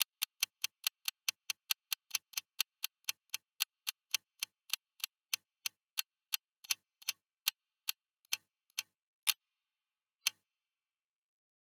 clock_slow.wav